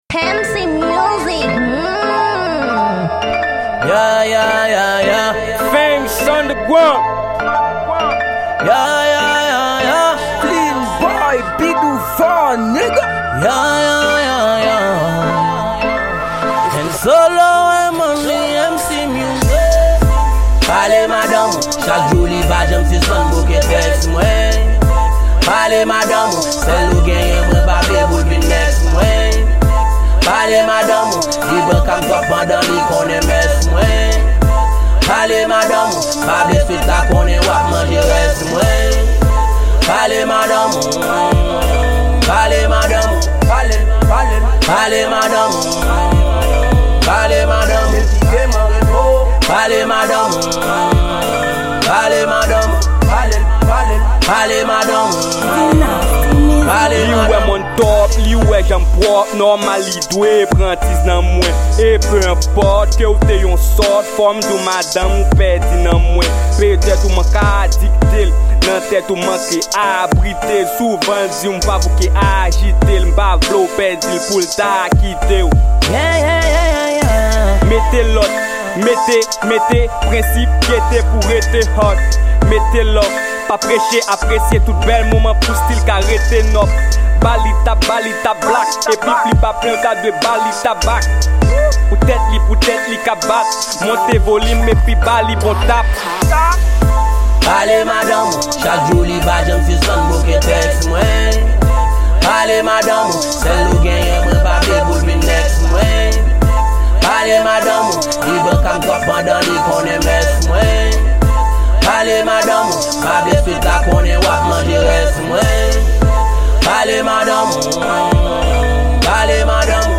Genre Trap